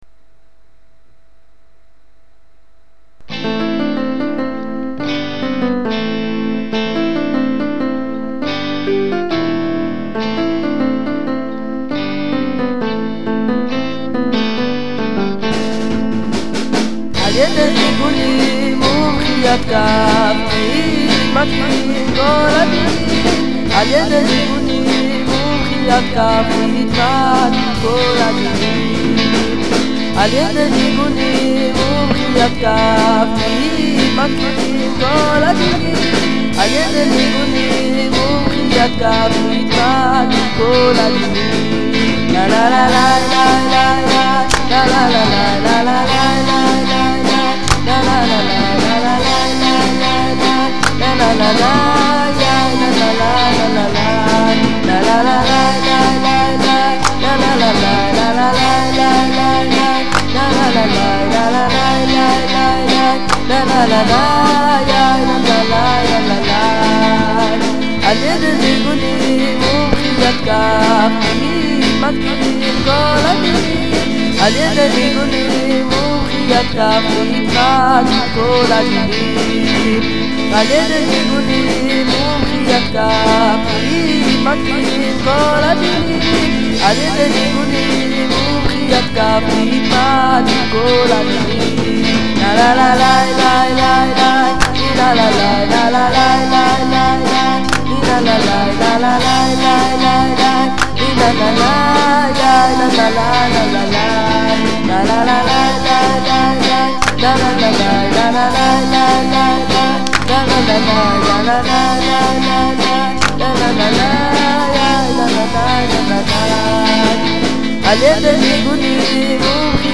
ממש אהבתי את המחיאת כף שיש בשיר...